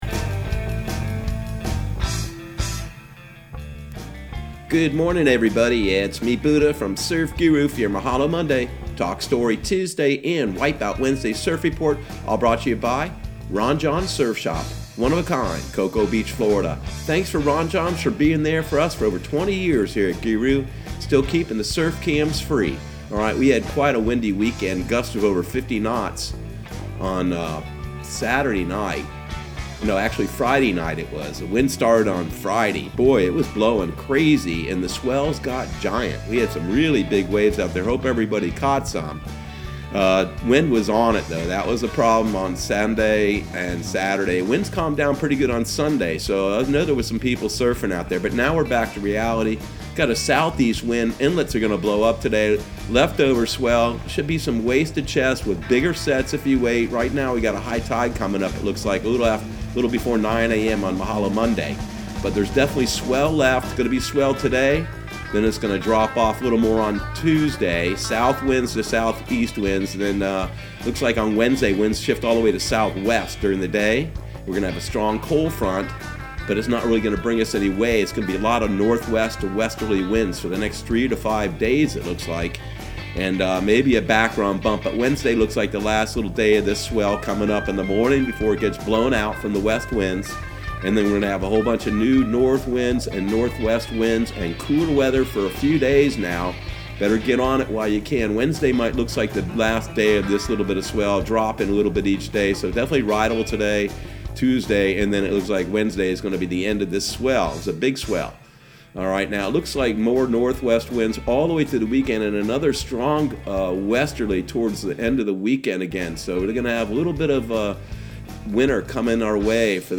Surf Guru Surf Report and Forecast 02/24/2020 Audio surf report and surf forecast on February 24 for Central Florida and the Southeast.